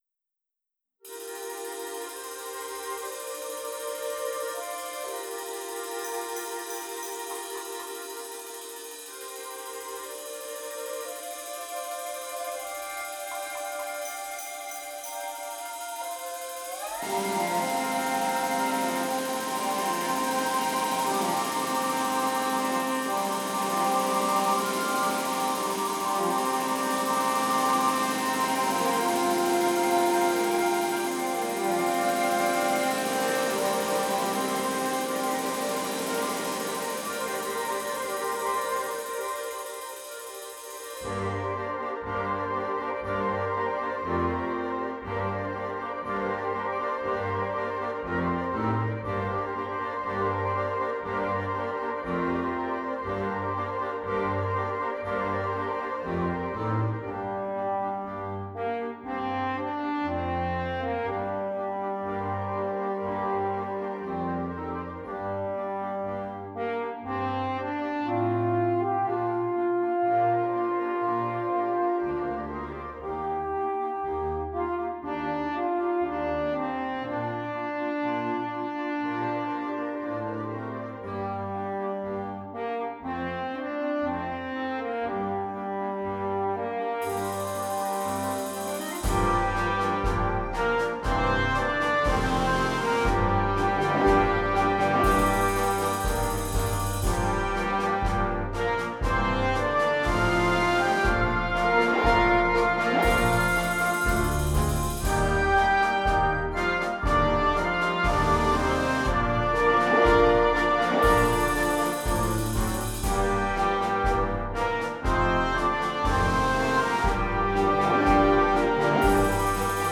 • Corno en F Solo
• Tuba
• Xilófono
• ·Palo de lluvia